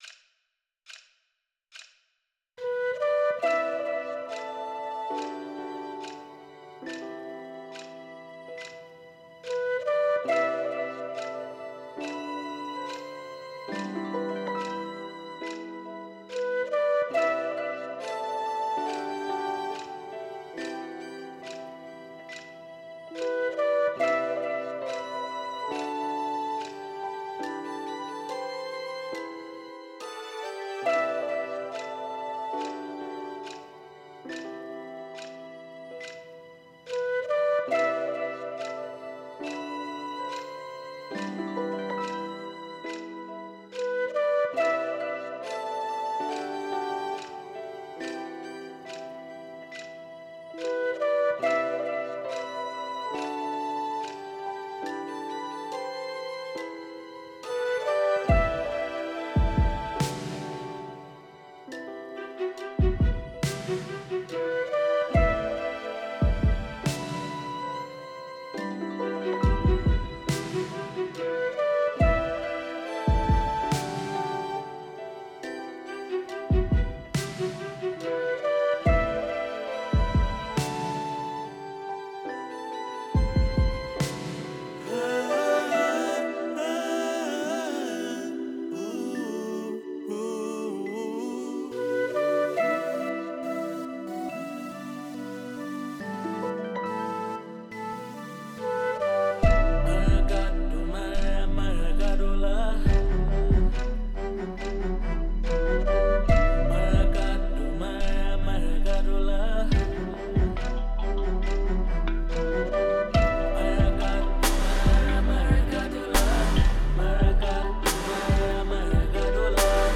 hip-hop
singing in Pitjantjatjara
• What do the drum sounds look like in your imagination?